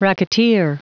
Prononciation du mot racketeer en anglais (fichier audio)